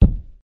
国内声音 " 大块的木头被扔下 03 1
描述：在一个水泥地板上下落的大槭树日志 用数字录音机录制并使用Audacity处理